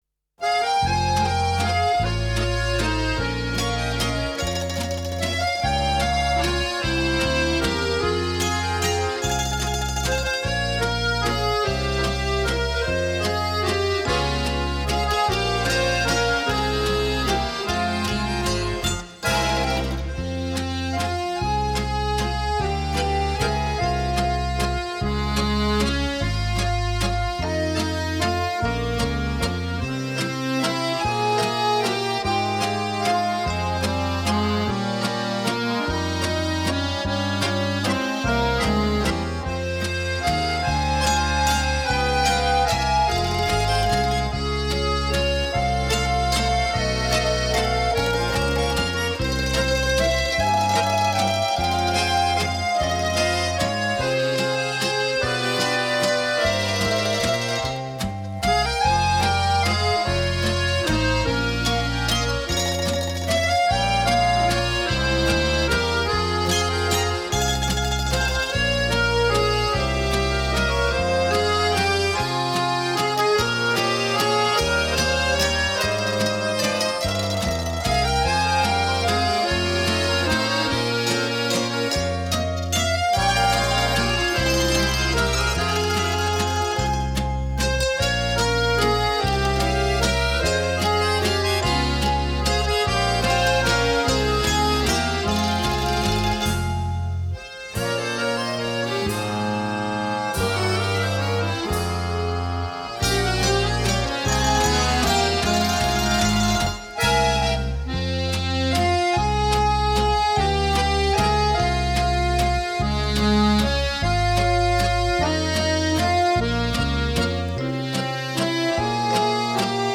Genre: Easy Listening, Instrumental